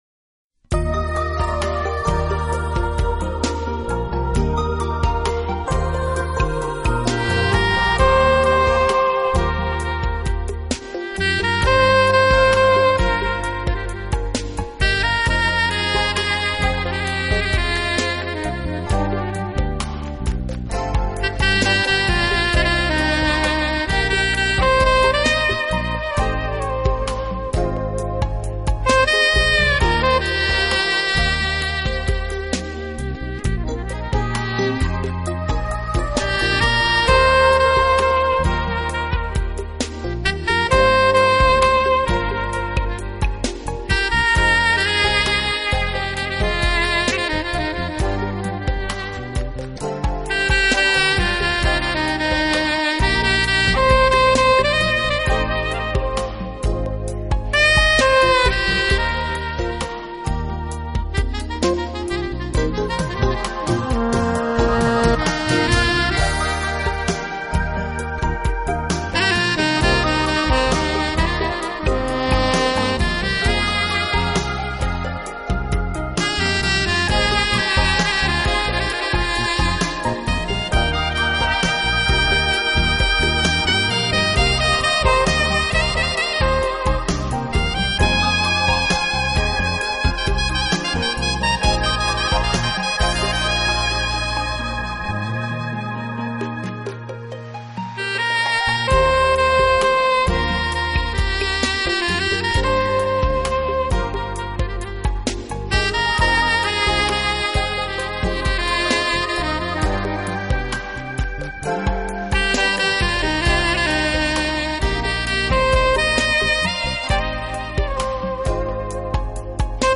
奔放的即興演奏，高超的演奏技巧